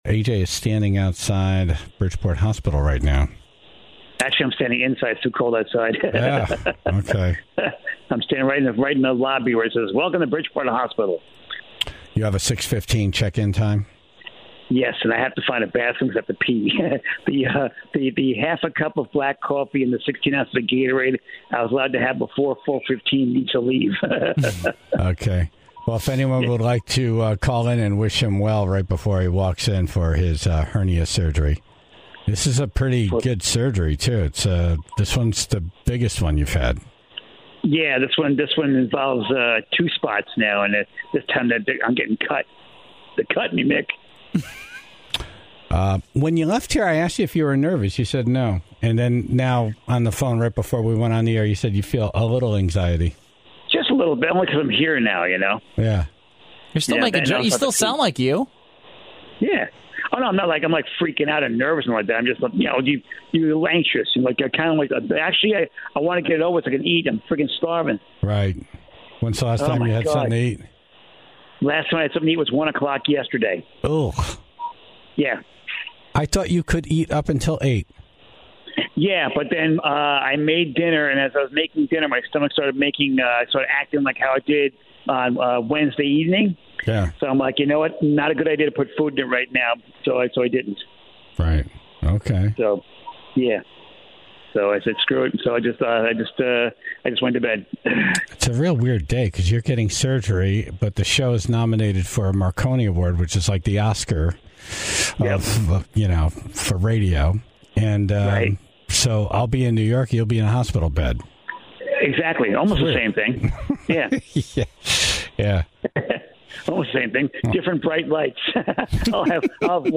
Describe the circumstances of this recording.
on the phone live from the hospital